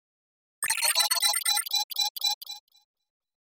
دانلود صدای ربات 67 از ساعد نیوز با لینک مستقیم و کیفیت بالا
جلوه های صوتی